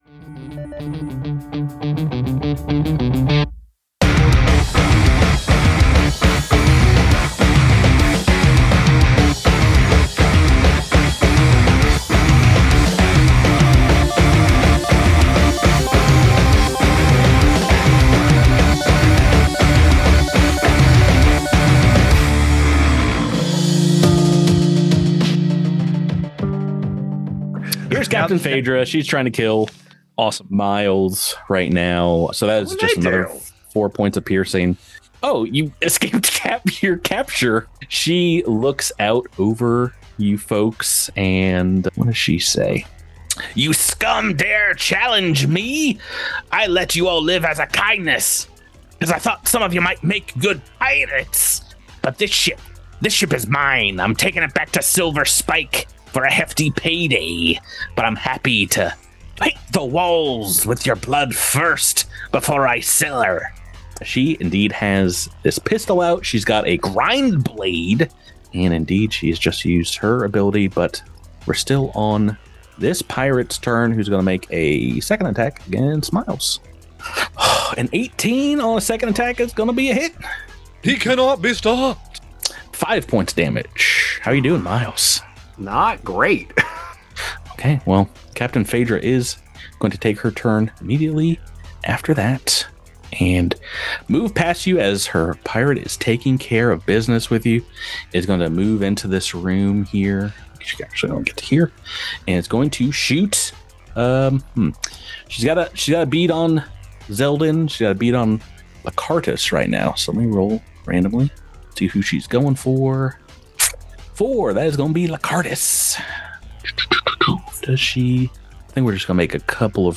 Cosmic Crit is a weekly Actual Play podcast centered on the new Starfinder RPG from Paizo. Listen to the shenanigans as a seasoned GM, a couple of noobs, and some RPG veterans explore the galaxy and fight monsters on behalf of the Starfinder Society. It's a little roleplay, a lot of natural 20s (we hope), and plenty of fun.